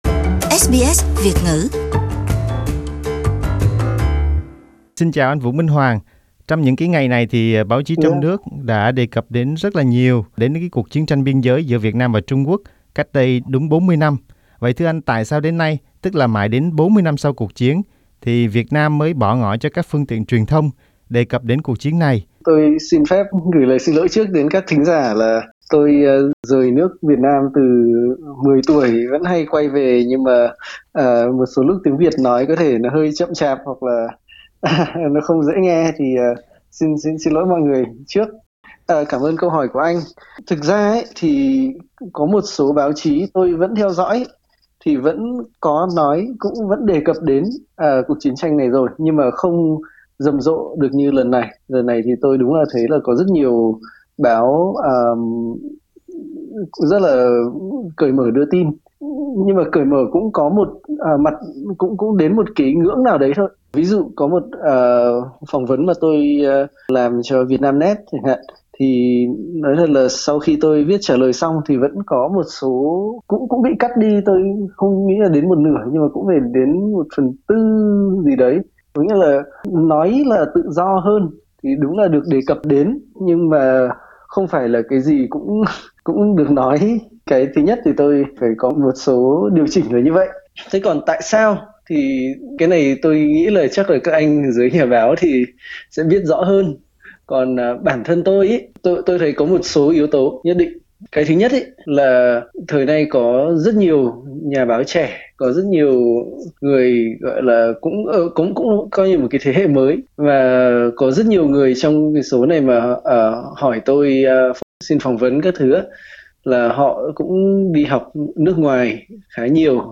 Hôm nay (17/2) là ngày cách đây vừa đúng 40 năm Trung Quốc khởi động cuộc chiến tranh ở biên giới với Việt Nam. SBS Việt ngữ đã có cuộc phỏng vấn